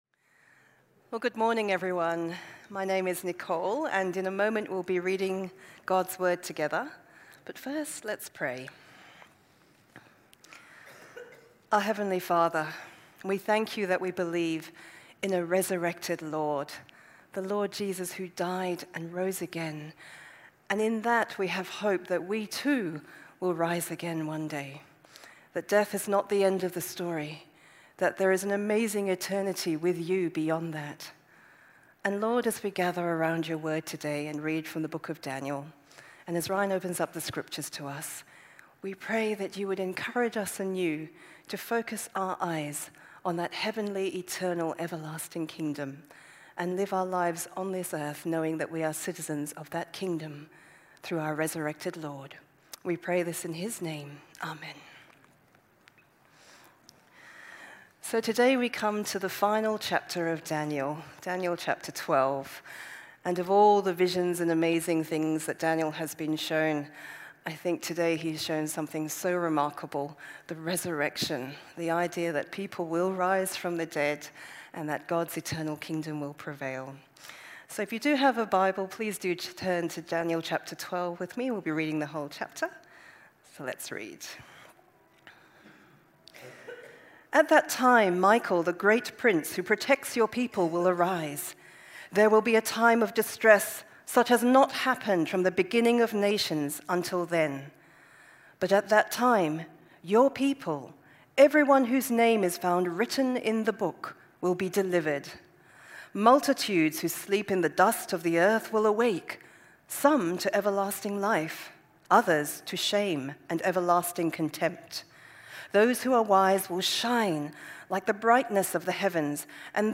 TalkWk9-ResurrectedFromExile.mp3